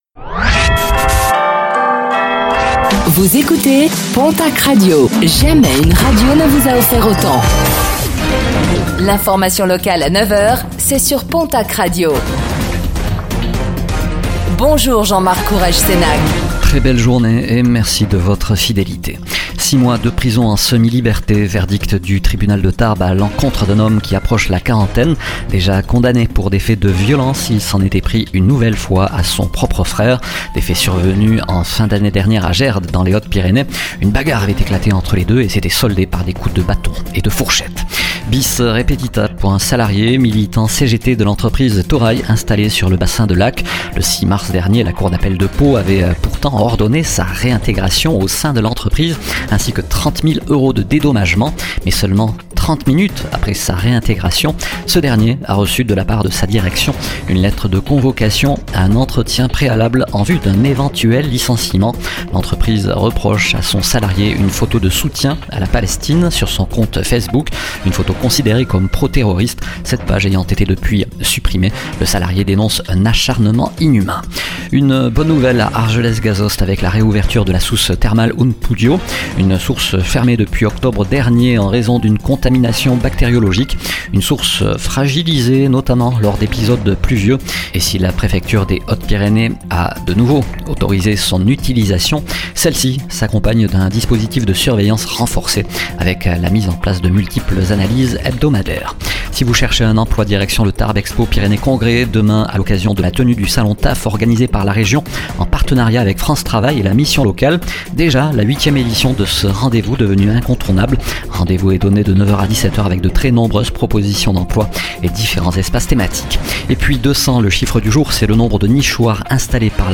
Infos | Mardi 1er avril 2025